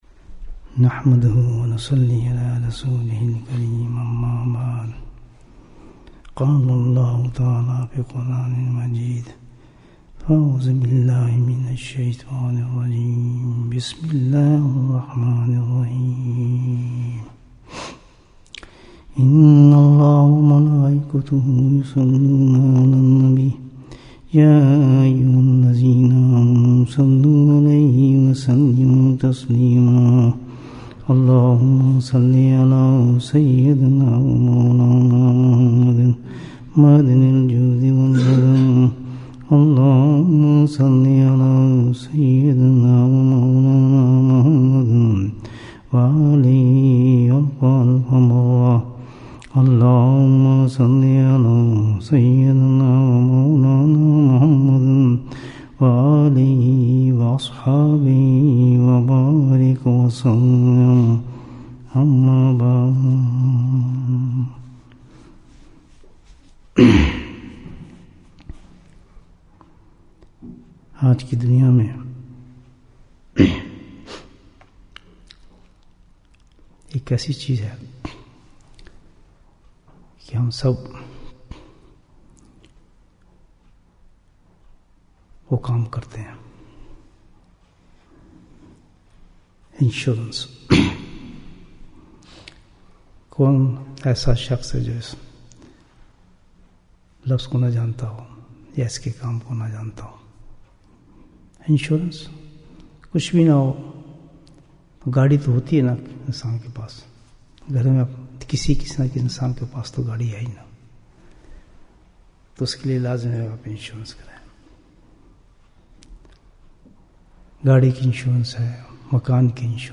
سب سے بہترین انشورنس کمپنی Bayan, 45 minutes5th November, 2016